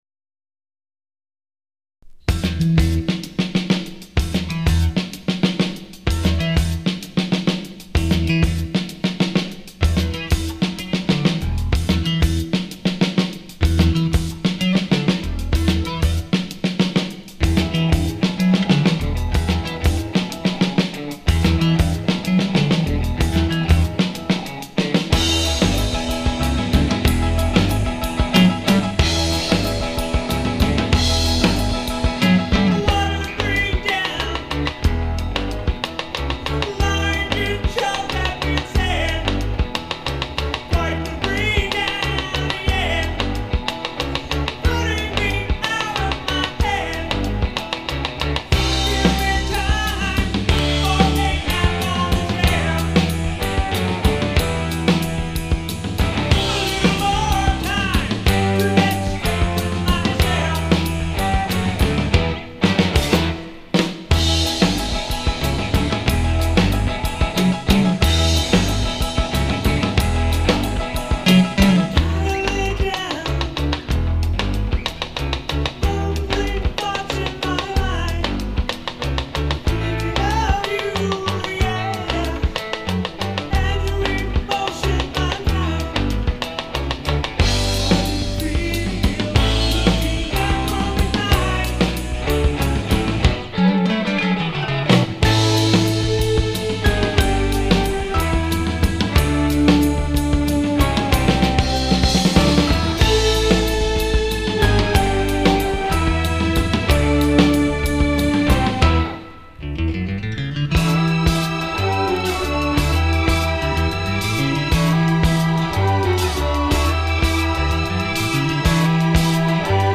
Lead Vocal
Keyboards